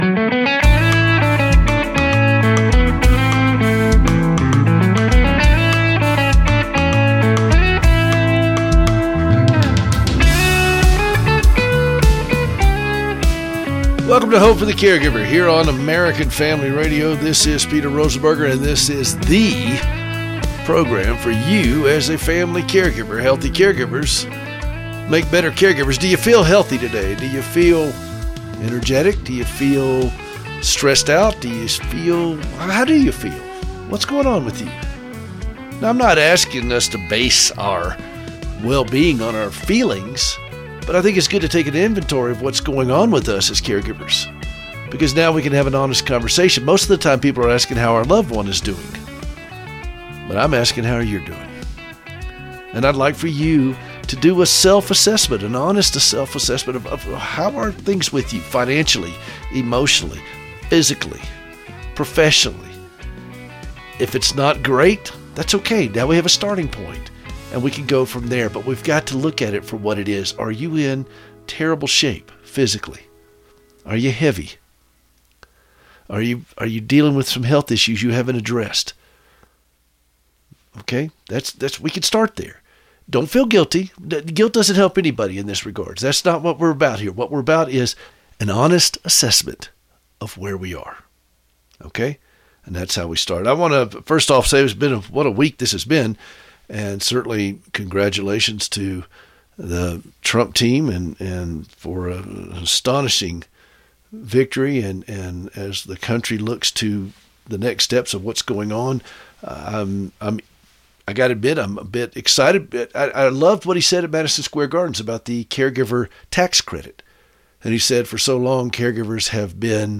LIVE on Saturday mornings at 7:00AM.